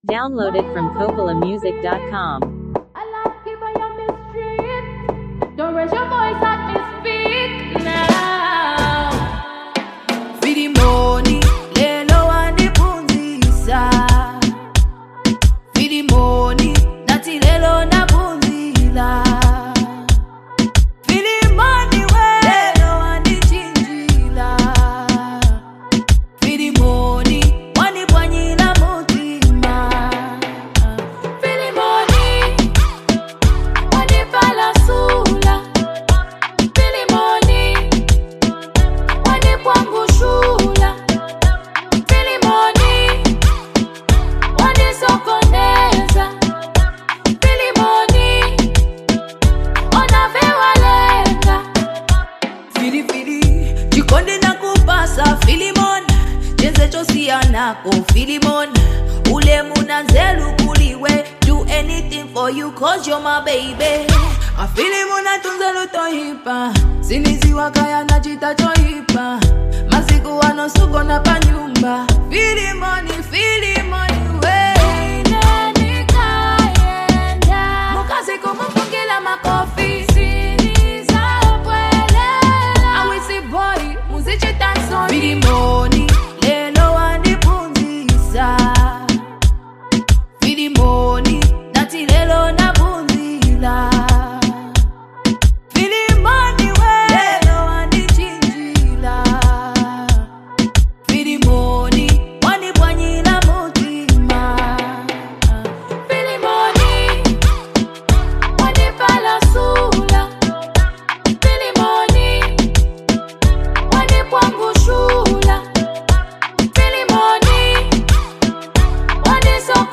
With a smooth melody and meaningful lyrics